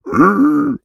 Minecraft Version Minecraft Version snapshot Latest Release | Latest Snapshot snapshot / assets / minecraft / sounds / mob / piglin / jealous2.ogg Compare With Compare With Latest Release | Latest Snapshot
jealous2.ogg